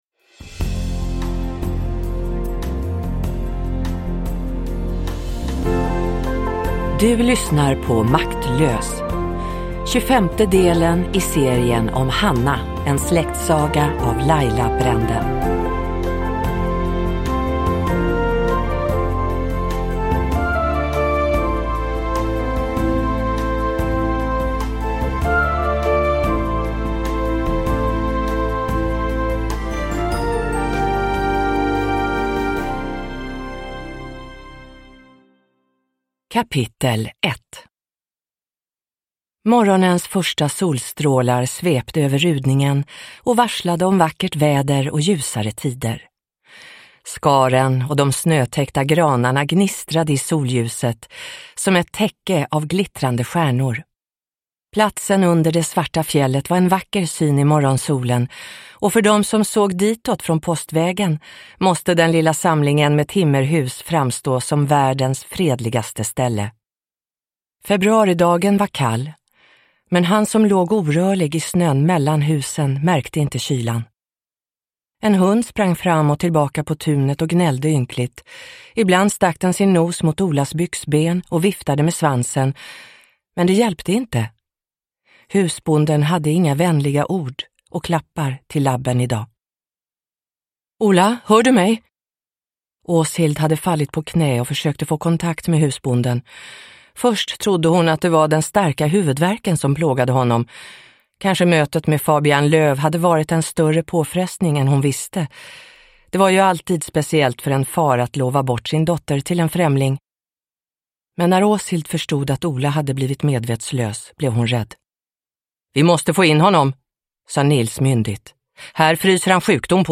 Maktlös – Ljudbok – Laddas ner